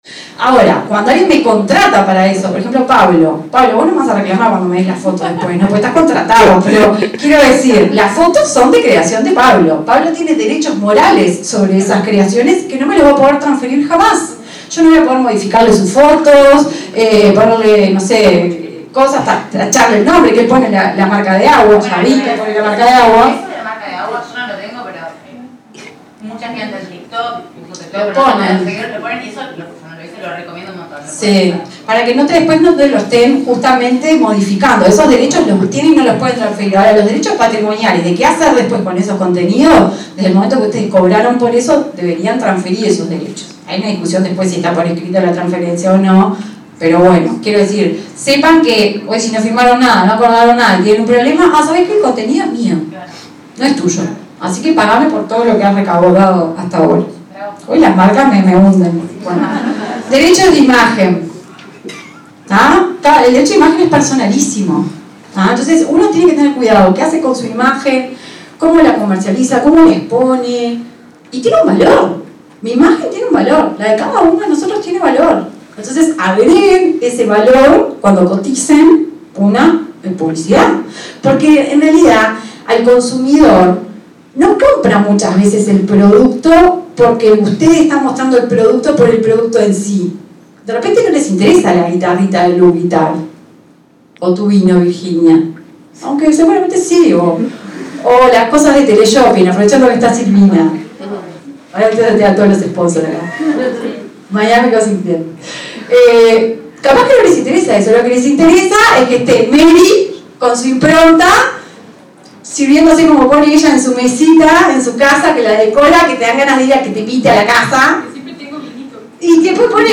El pasado 22 de agosto, el hotel Radisson Victoria Plaza fue sede del primer workshop para influencers en Uruguay, un evento pionero que reunió a destacados speakers nacionales e internacionales.